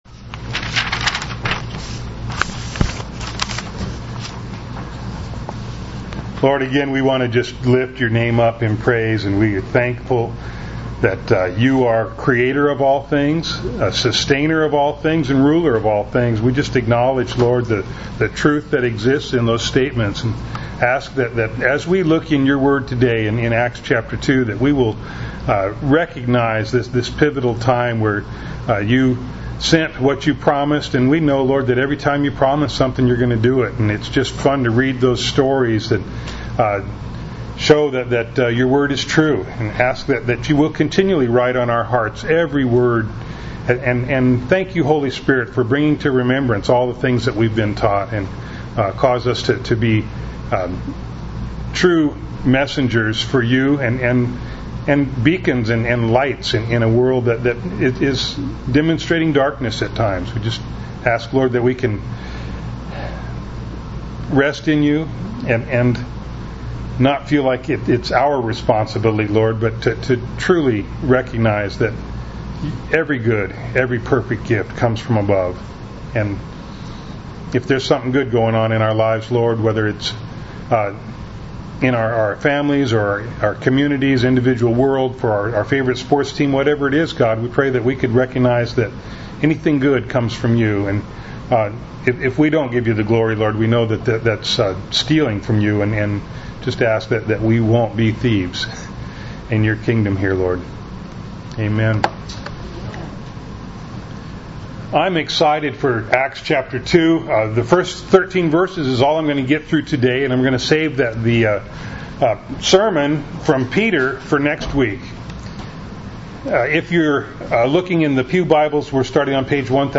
Acts Passage: Acts 2:1-13 Service Type: Sunday Morning Bible Text